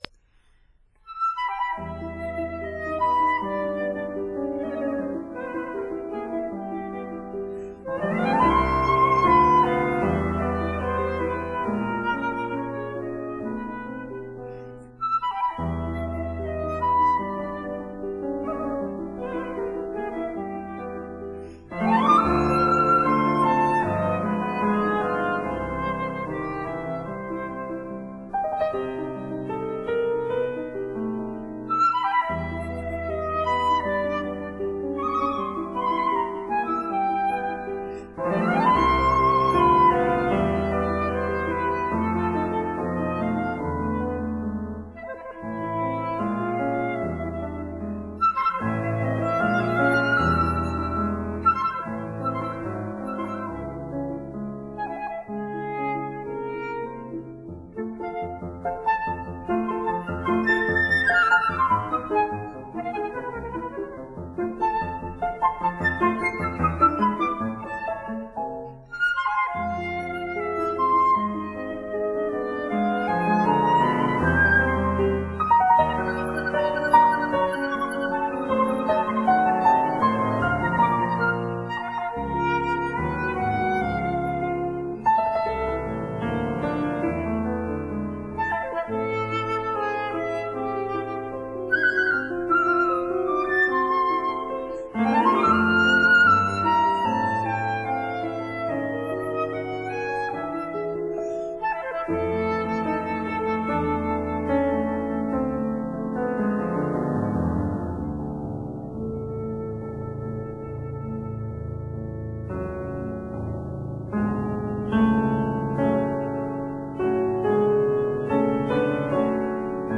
Trio for Flute, Cello and Piano
Sonata for Flute and Piano
Sonata for Cello and Piano
flute
cello
piano